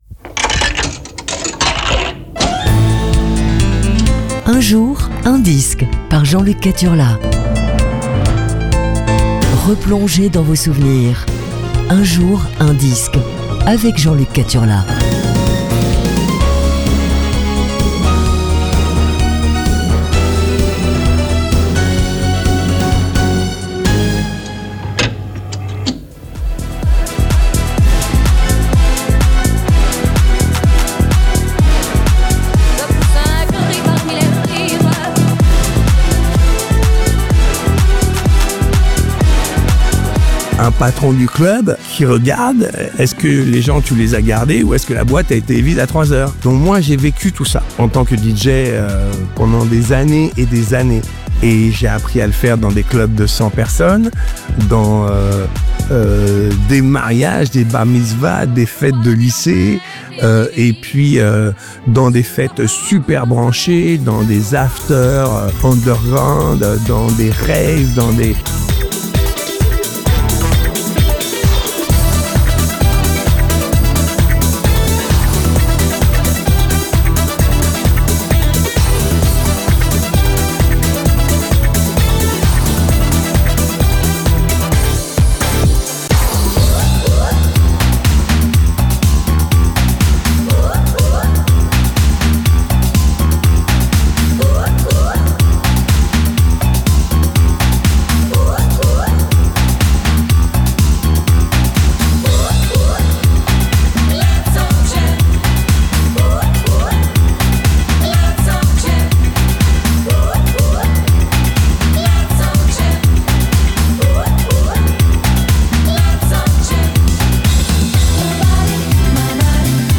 Aujourd'hui c'est Disco Funk - 4